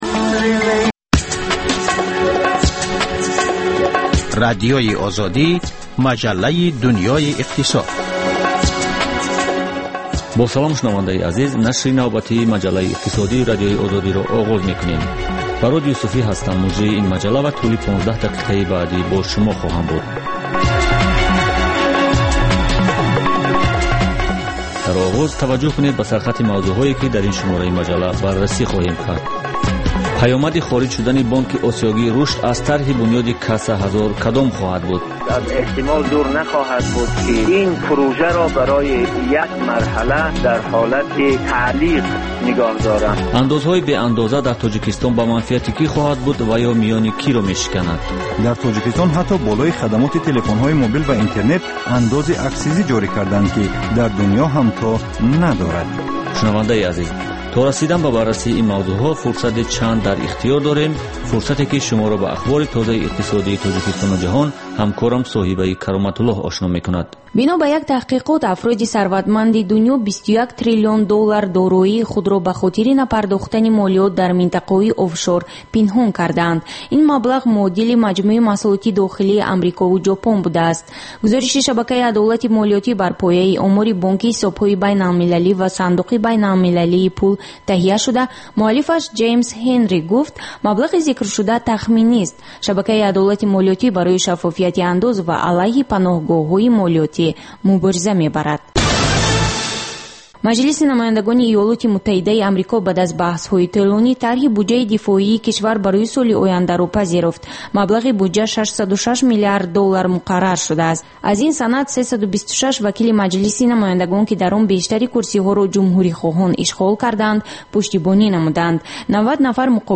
Дар маҷаллаи Дунёи иқтисод коршиносон, масъулони давлатӣ ва намояндагони созмонҳои марбутаи ғайридавлатию байналмилалӣ таҳаввулоти ахири иқтисоди кишварро баррасӣ мекунанд.